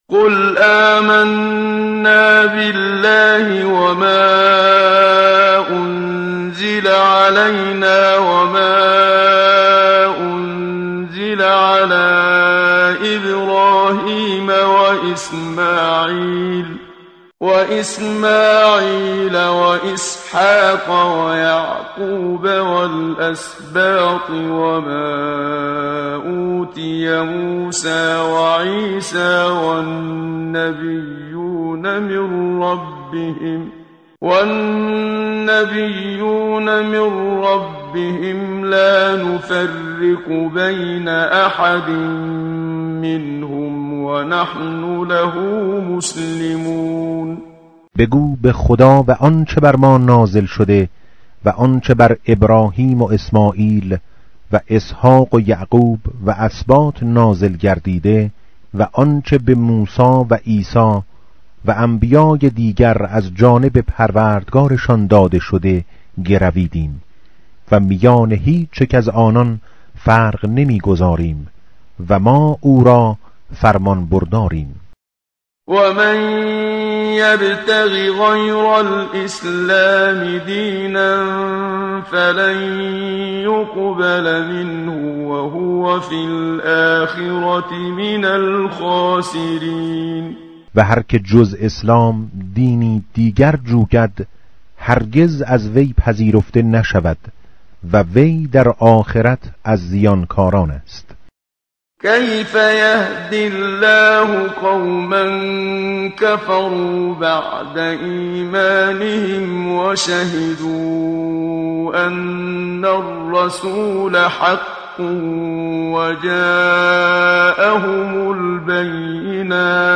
tartil_menshavi va tarjome_Page_061.mp3